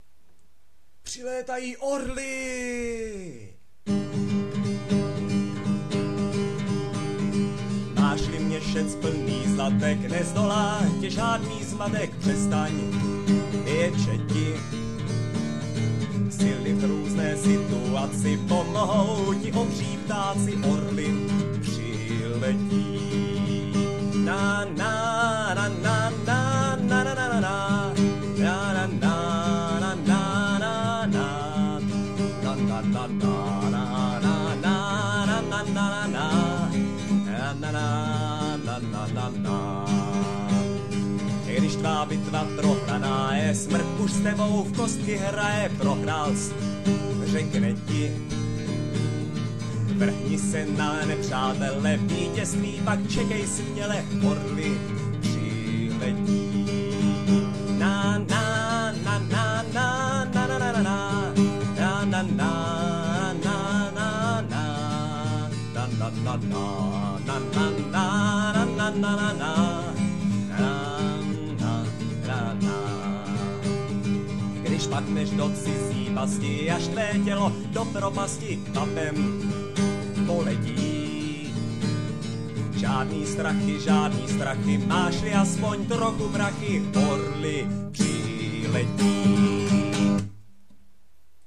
Kytarová verze